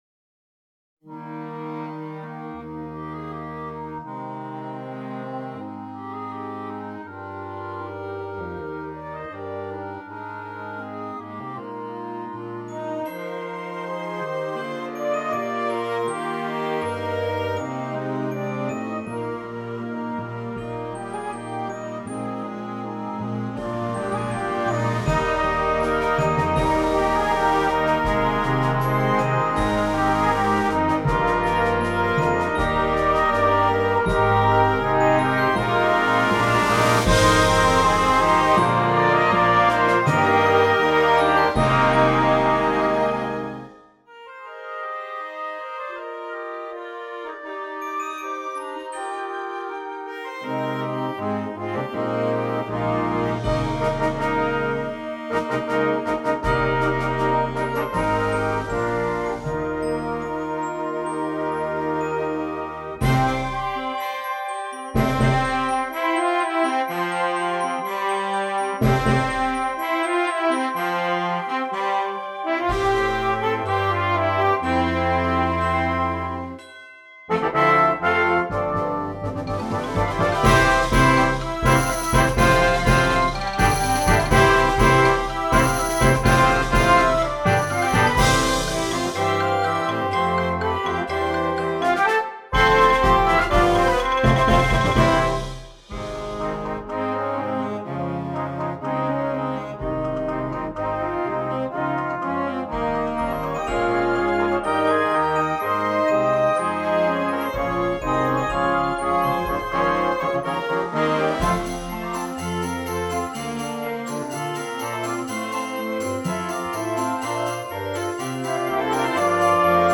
Gattung: Konzertant
Besetzung: Blasorchester